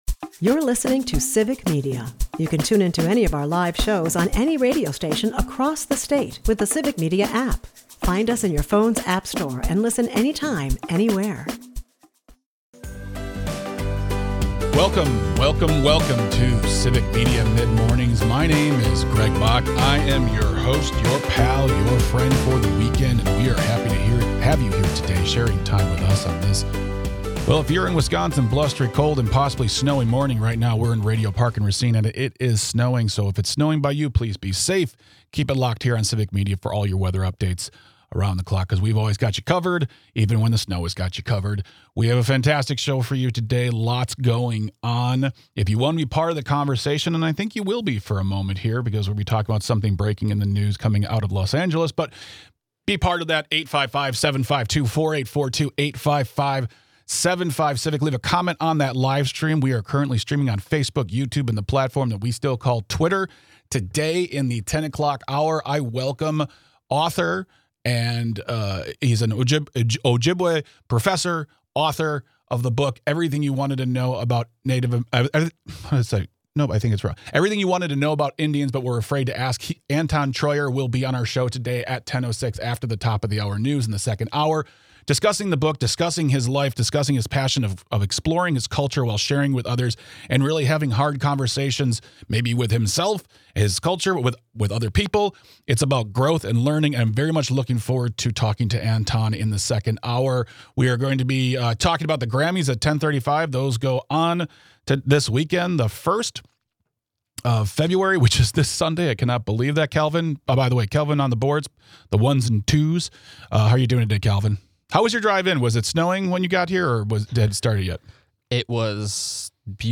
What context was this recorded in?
We also talk about the time for unity, even when that means putting down your pride. As always, thank you for listening, texting and calling, we couldn't do this without you!